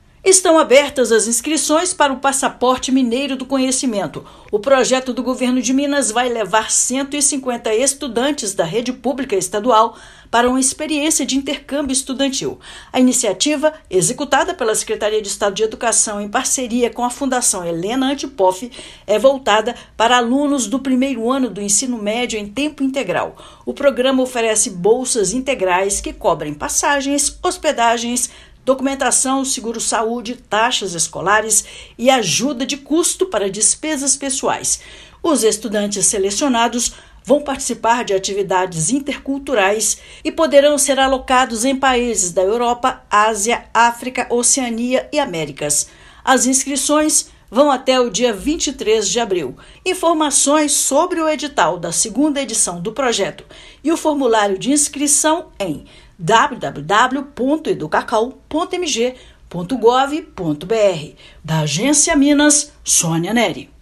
Projeto oferta 150 bolsas de intercâmbio estudantil, com despesas pagas pelo Estado, para estudantes matriculados no 1º ano do EMTI. Ouça matéria de rádio.